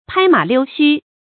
拍馬溜須 注音： ㄆㄞ ㄇㄚˇ ㄌㄧㄨ ㄒㄩ 讀音讀法： 意思解釋： 比喻諂媚奉承。